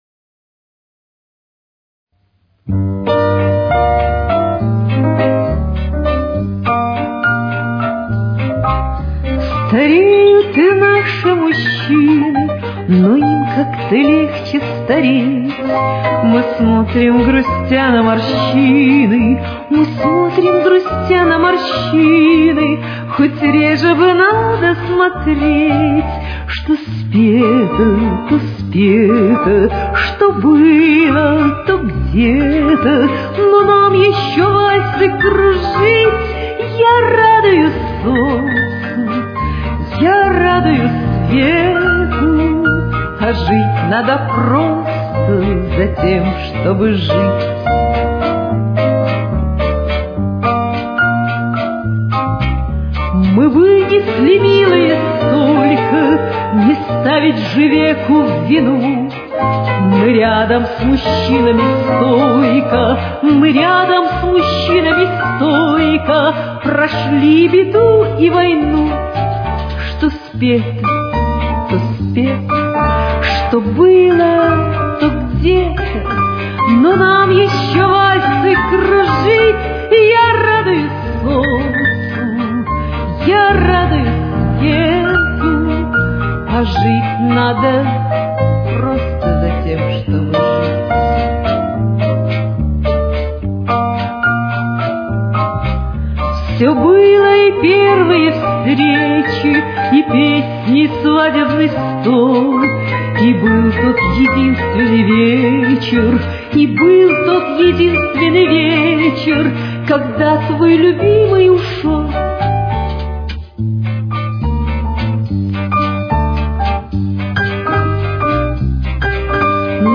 Темп: 213.